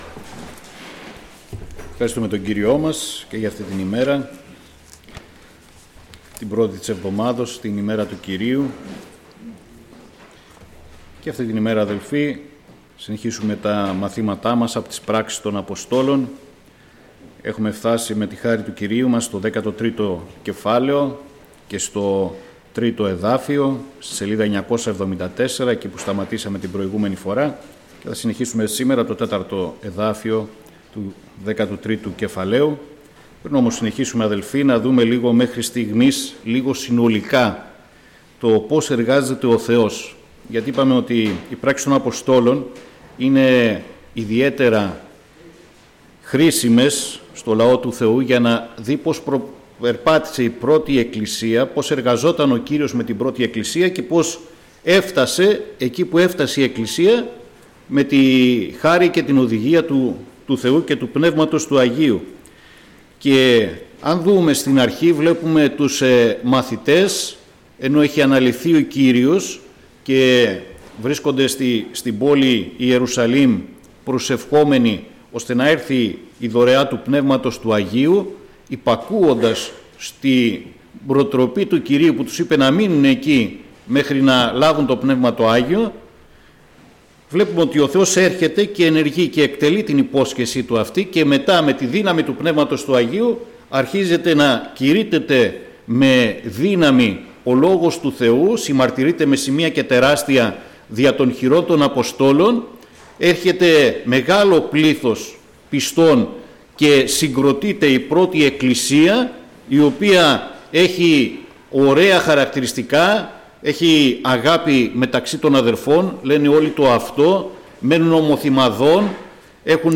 Ομιλητής: Διάφοροι Ομιλητές